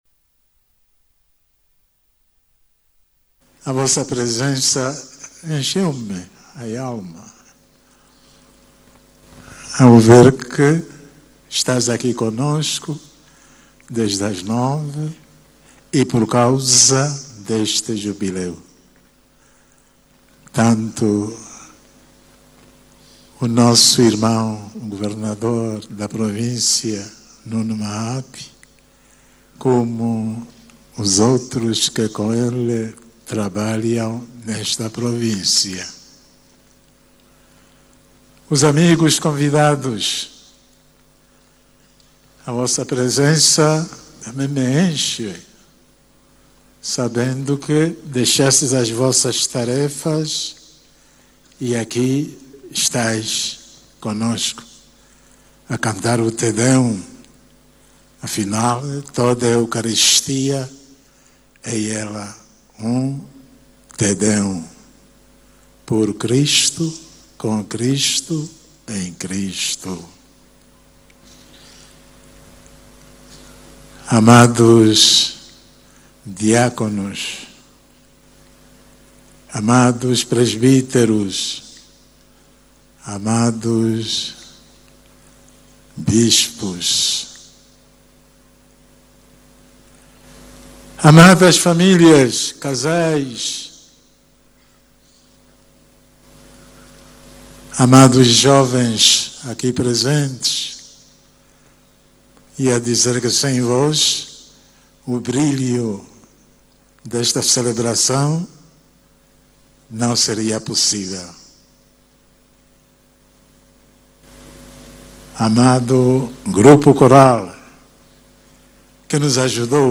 Oiça a mensagem de Dom Zacarias Kamuenho.